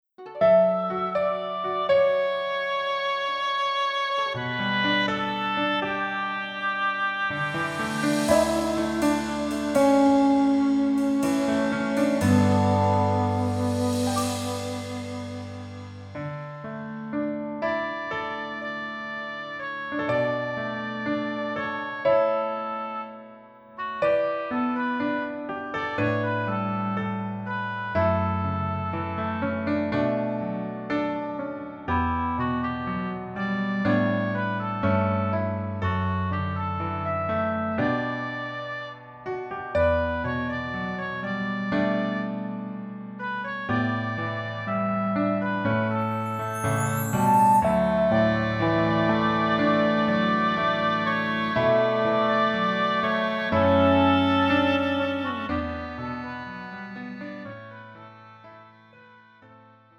음정 남자키
장르 가요 구분 Pro MR